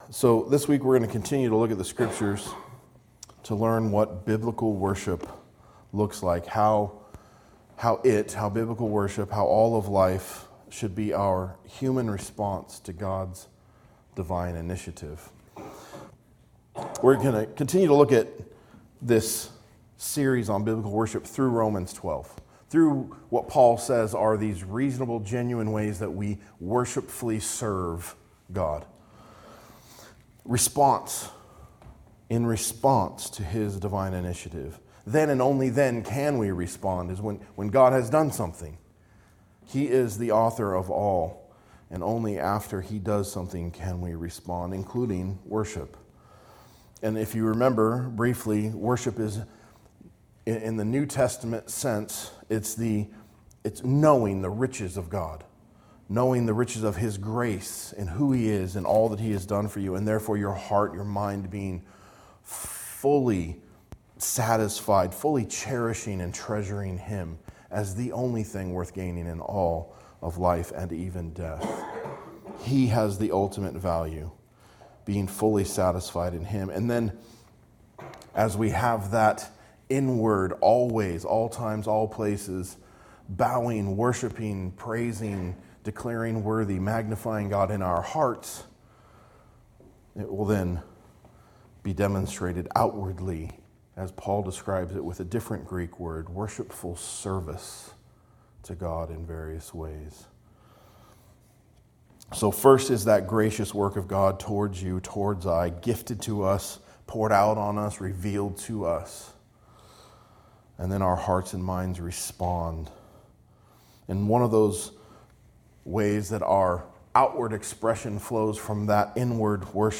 A message from the series "Biblical Worship Series." We worship God outwardly by generously caring for the church and showing gospel grace and love for strangers.